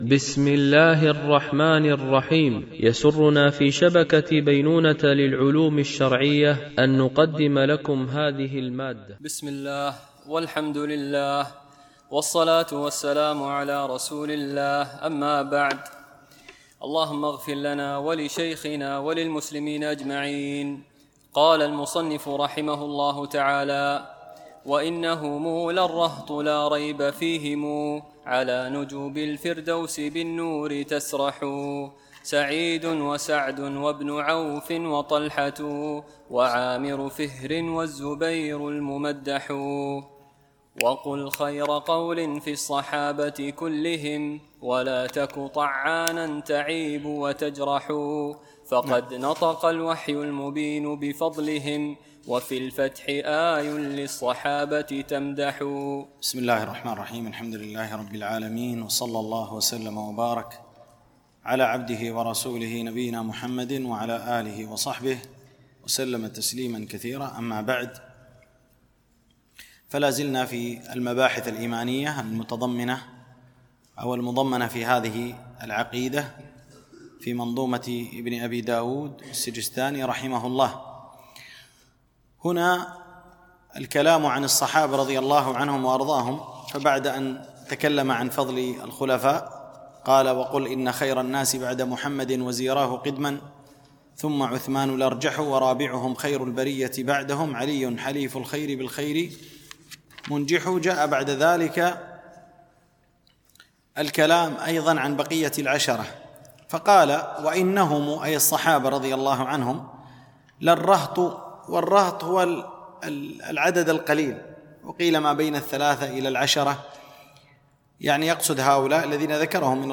مباحث إيمانية - الدرس 29